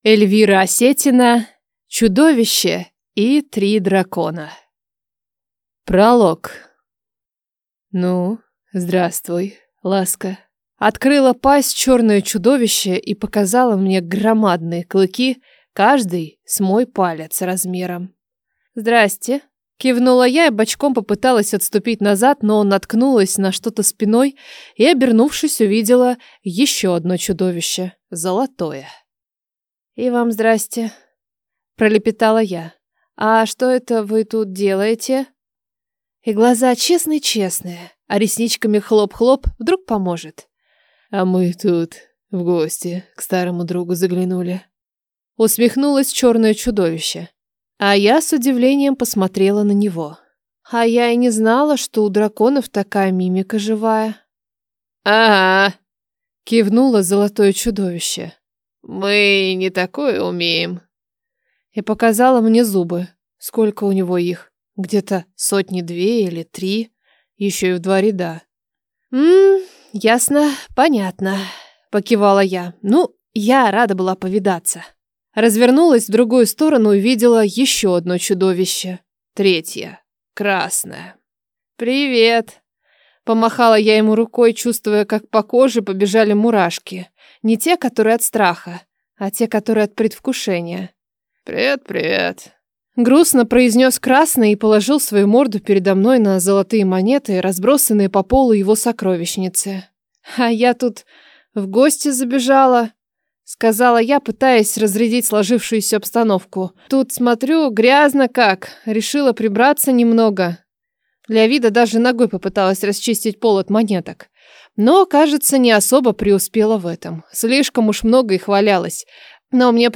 Аудиокнига Чудовище и три дракона | Библиотека аудиокниг